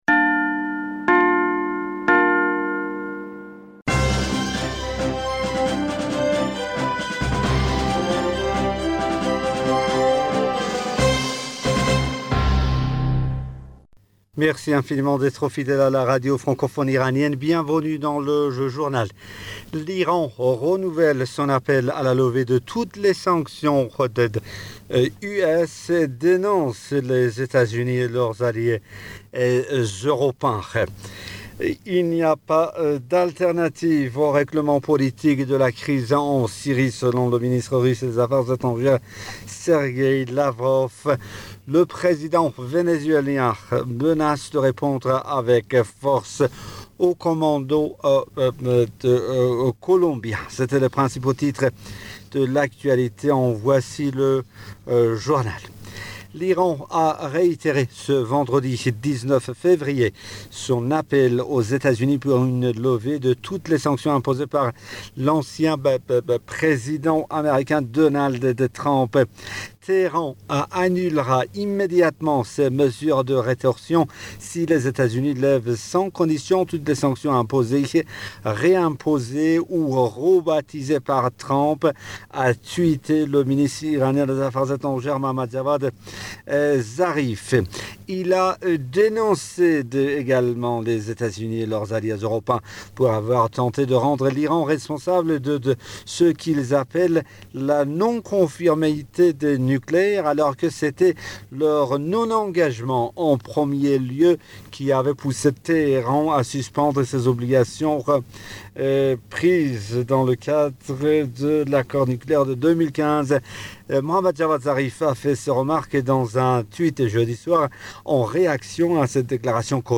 Bulletin d'informationd u 19 Février 2021